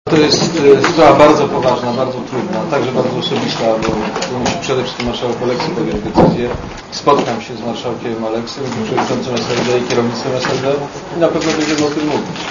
"To trudna sprawa, na razie nie chcę jej komentować" - mówi Aleksander Kwaśniewski o wyroku Sądu Lustracyjnego na Marszałka Sejmu.
Komentarz audio